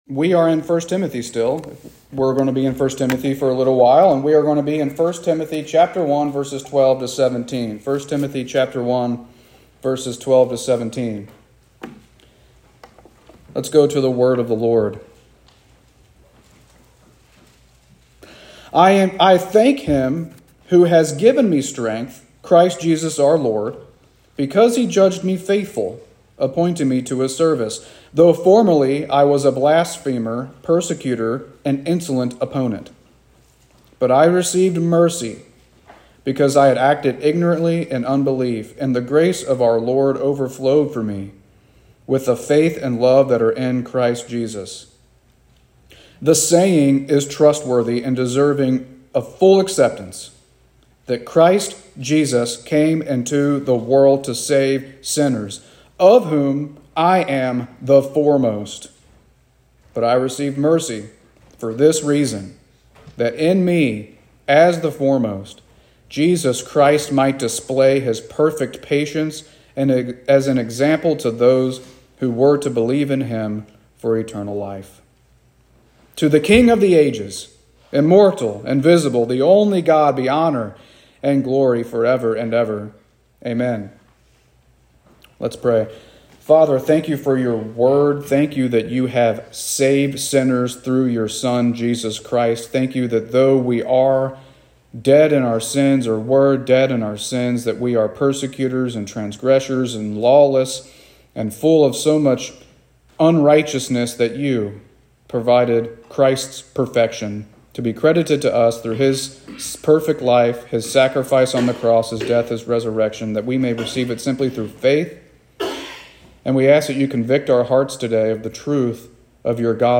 Sermons | Monterey Baptist Church